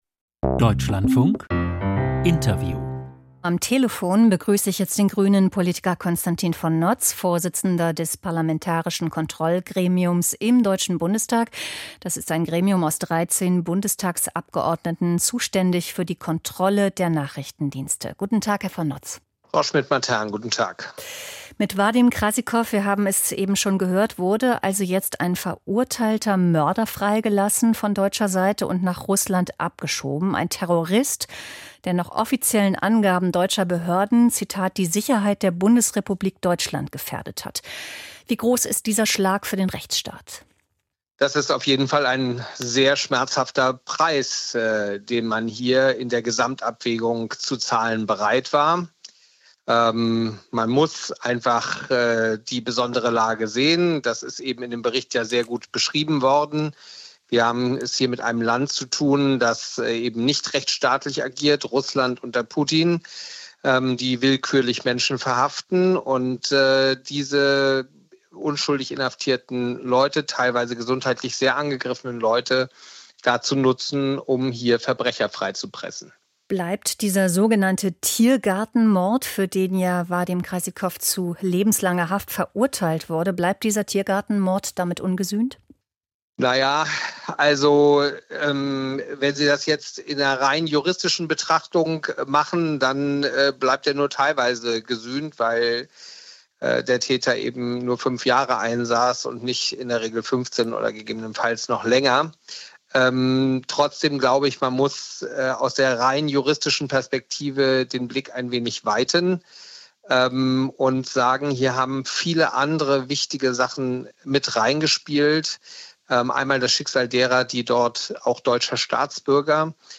Gefangenenaustausch: Bitterer Beigeschmack? Interv. Konstantin von Notz (Grüne)